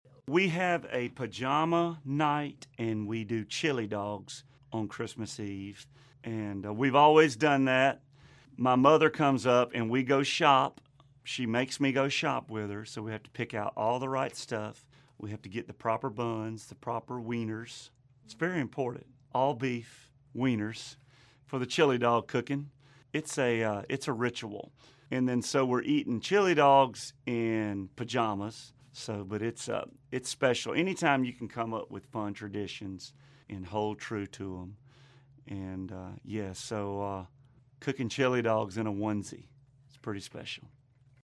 Audio / Luke Bryan reveals his family’s Christmas tradition.